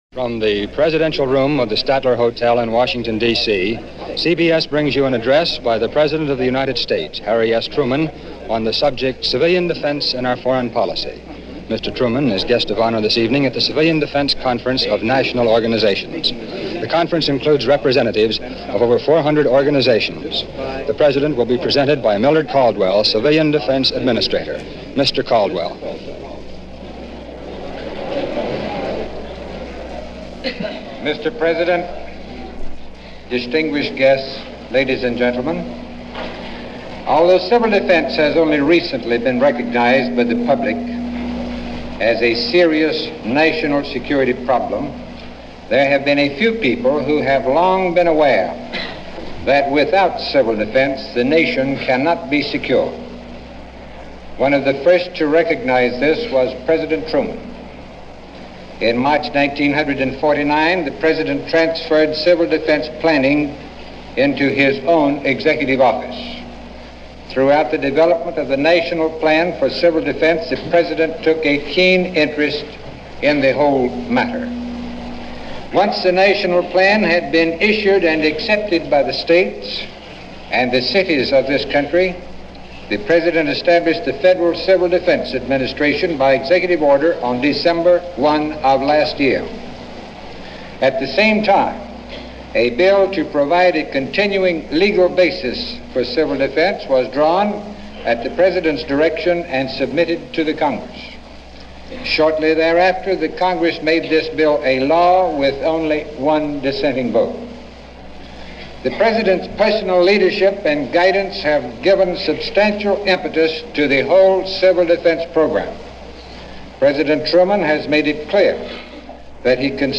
So on May 7, 1951 at a Dinner held during the Civil Defense Conference in Washington D.C., President Truman addressed the gathering and spoke about the then-current atmosphere and apprehension: President Truman: This conference is being held to consider one of the most important tasks facing our country.